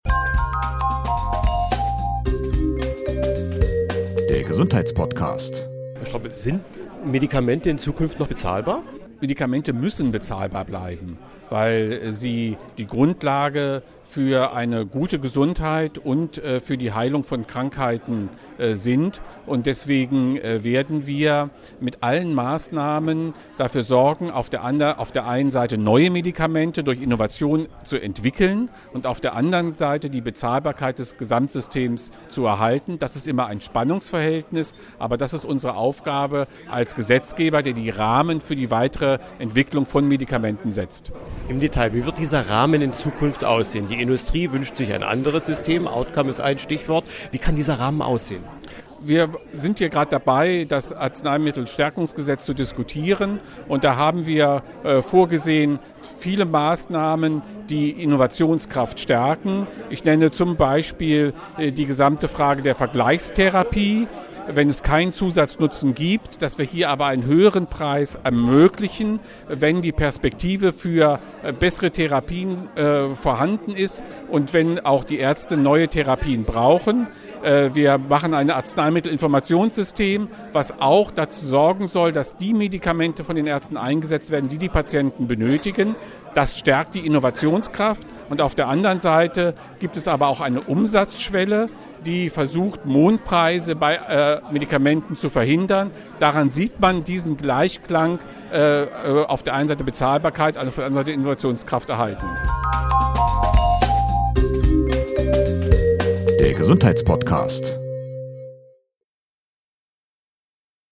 Lutz Stroppe, Staatssekretär im Bundesministerium für Gesundheit